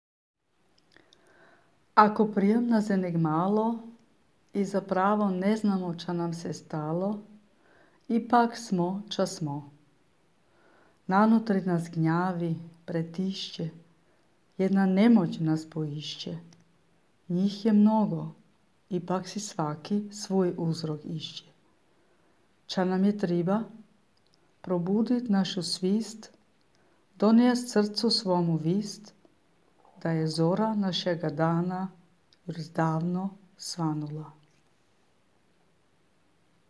Klimpuh, u aprilu 2026.